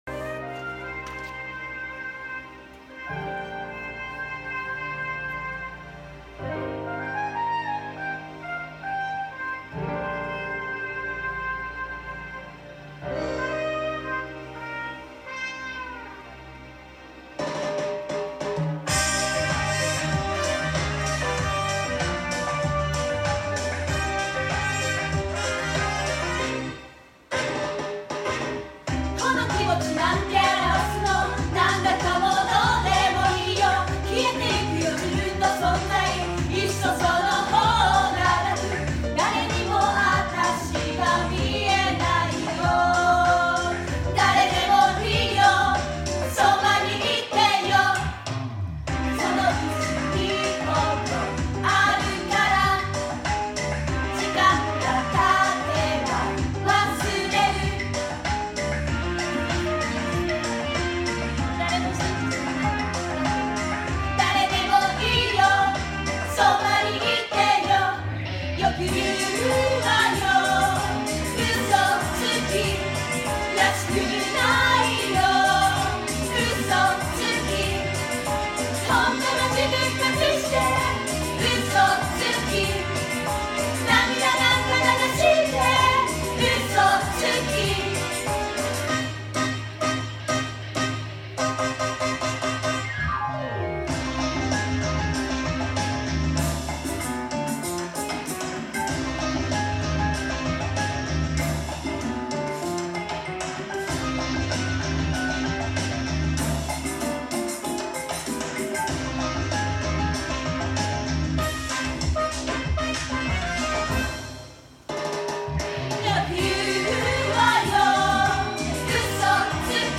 歌